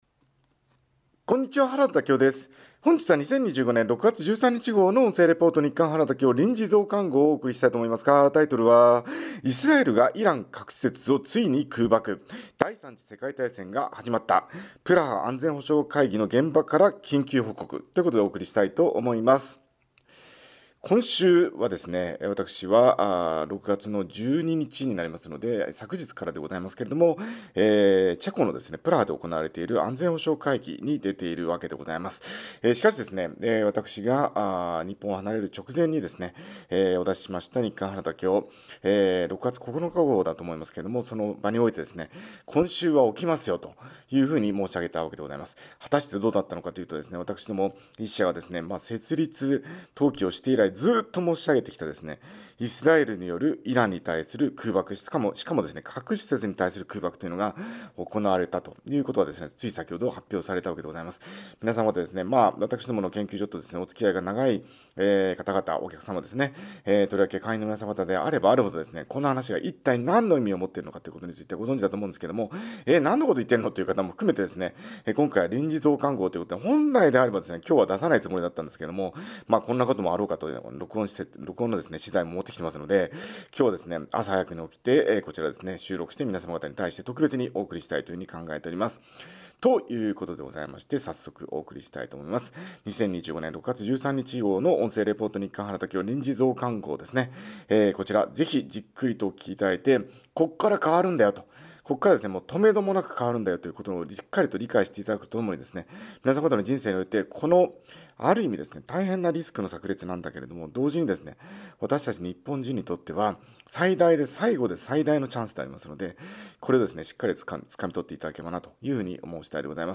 音声レポート「緊急増刊号」2025年6月13日号
プラハ安全保障会議の現場から緊急報告！！」